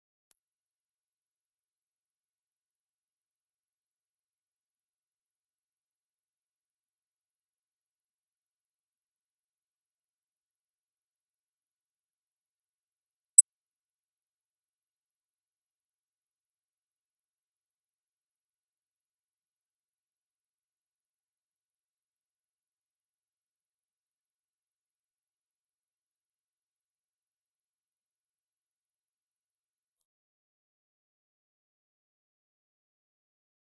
Эти аудиозаписи содержат высокочастотные сигналы и другие звуковые эффекты, неприятные для насекомых.
Ультразвук, негативно воздействующий на рыжих тараканов